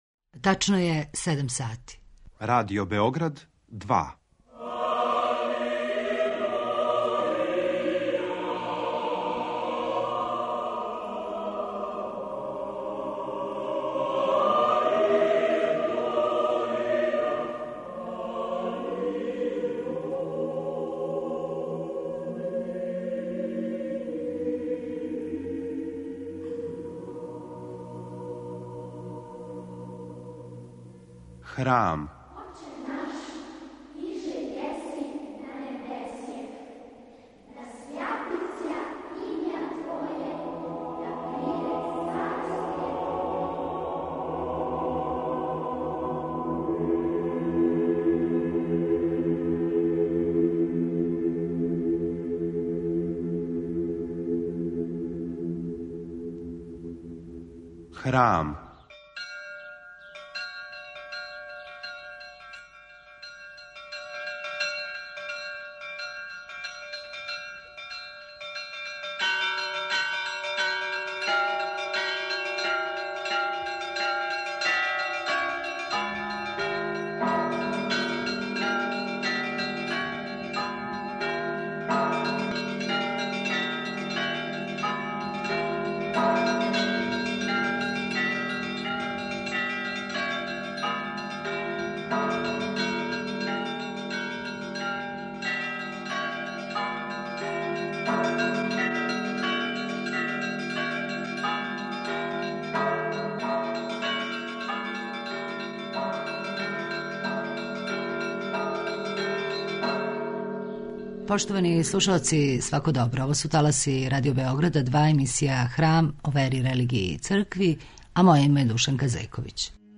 На почетку овонедeљног Храма, поводом Божића, чућемо надбискупа београдског Станислава Хочевара. У наставку емисије отварамо календарско питање: од Гаја Јулија Цезара, преко Папе Гргура XIII, стижемо до знаменитог научника Милутина Миланковића.